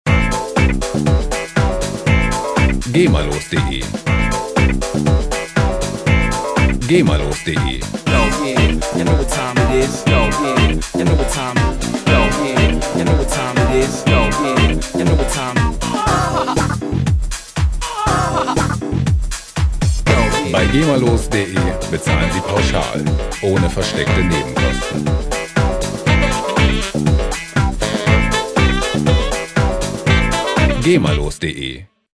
Funk Loops GEMA-frei
Musikstil: Dance
Tempo: 120 bpm